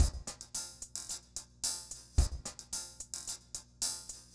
RemixedDrums_110BPM_34.wav